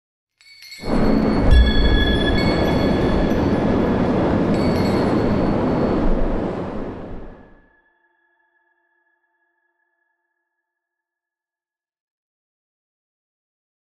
abjuration-magic-sign-rune-intro-fade.ogg